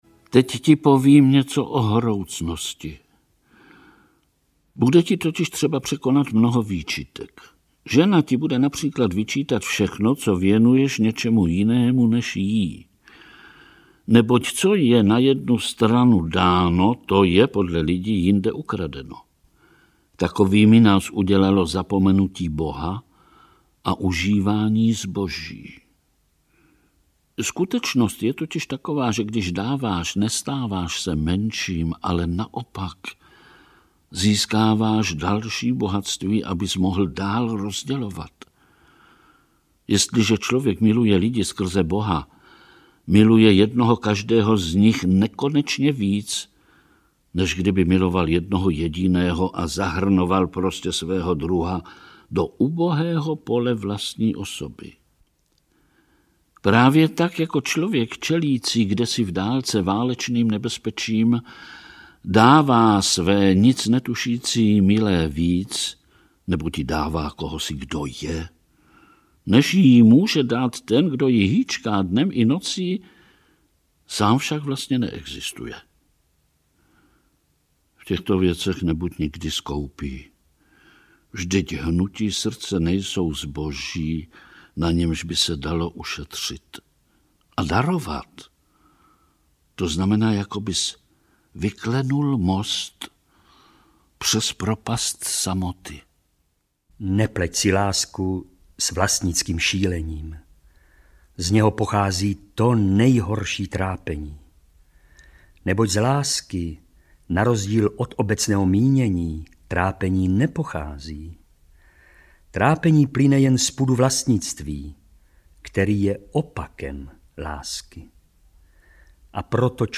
Myšlenky z Citadely Antoina de Saint-Exupéryho audiokniha
Ukázka z knihy
• InterpretVlastimil Brodský, Grabriela Vránová, Miloš Horanský, Antonie Hegerlíková